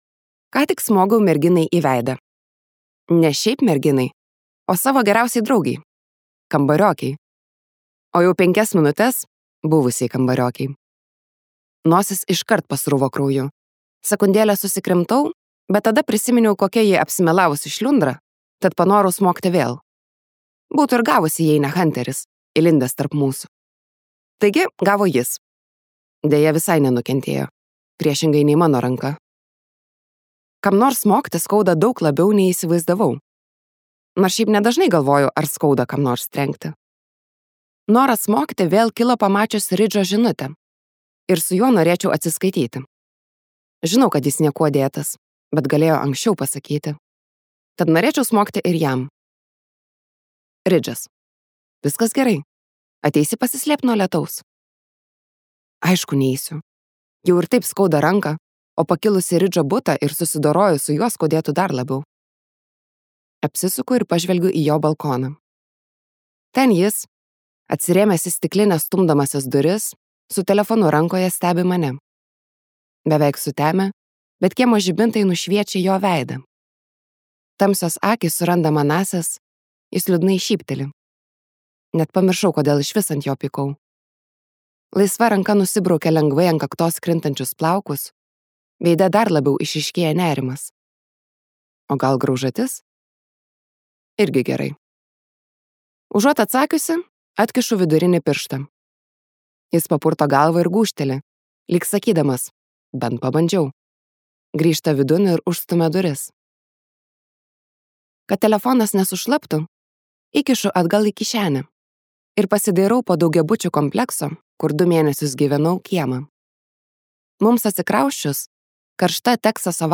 Colleen Hoover audioknyga „Galbūt kažkada“. Tai meilės romanas, kuriame pasakojama apie išdavystės pakirstą Sidnės Bleik gyvenimą.